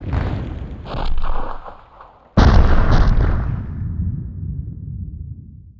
monster2 / fire_dragon / dead_2.wav